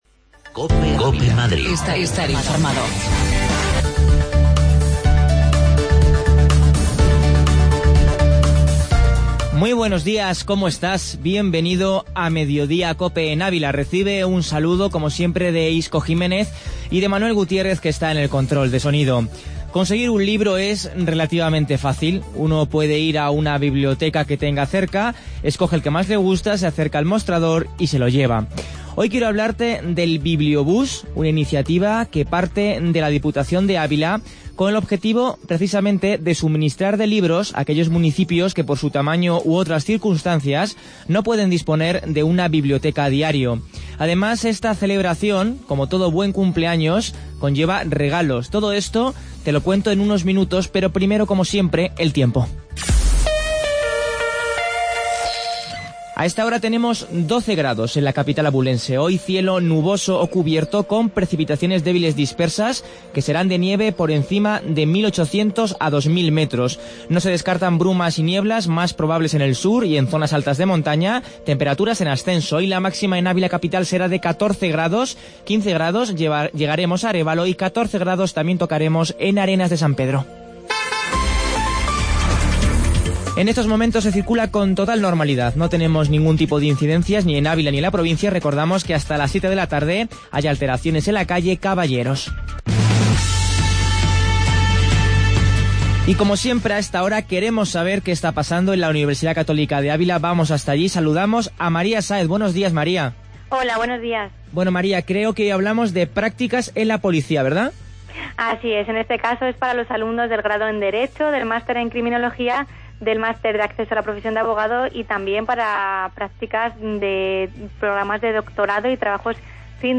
AUDIO: Entrevista BiblioBús